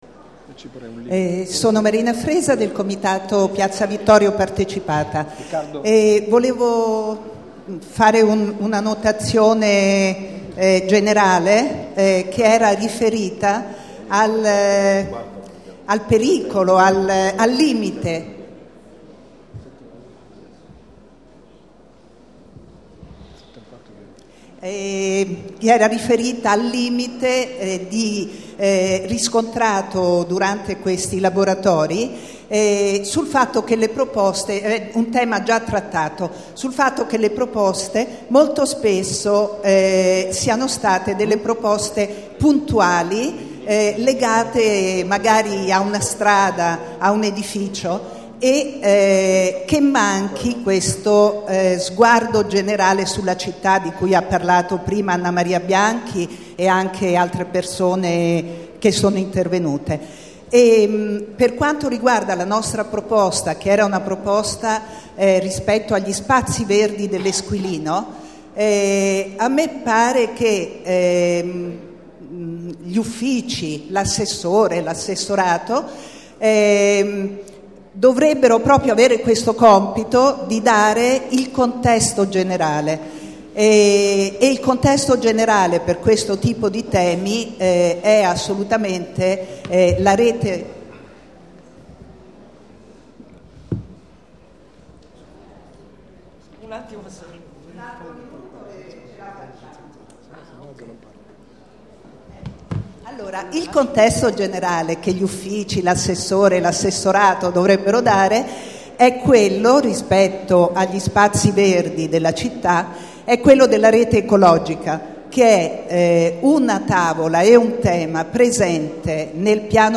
Registrazione integrale dell'incontro svoltosi il 19 dicembre 2014 presso la Casa della Città, in P.za Da Verrazzano, 7.